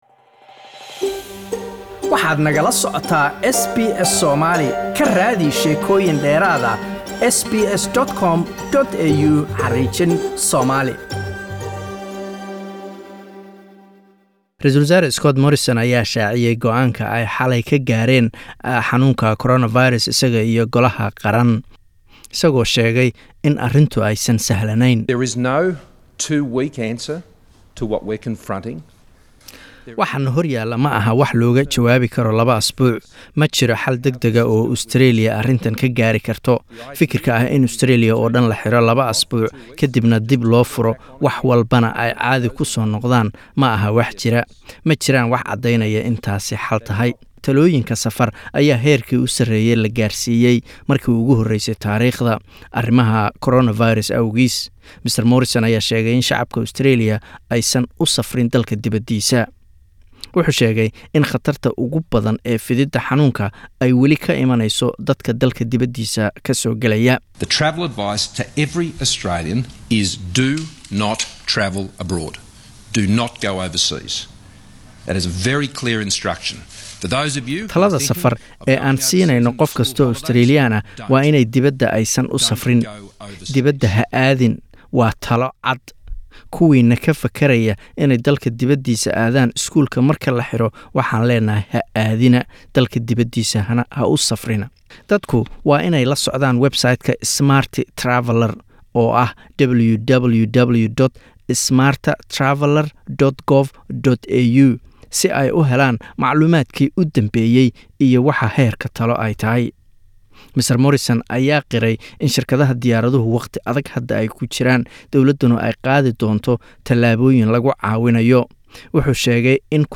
PM coronavirus press conference